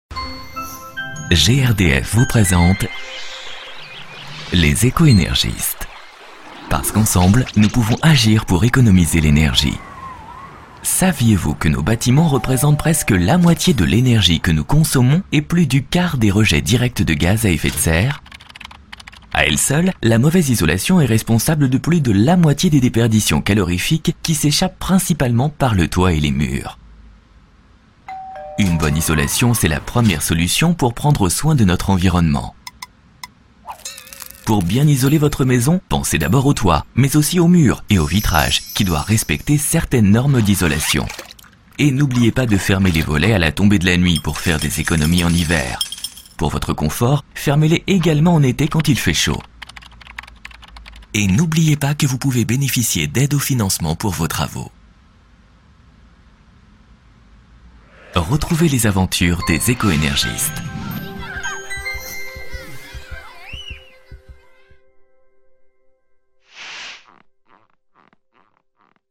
ERDF clair, bienveillant - Comédien voix off
Genre : voix off.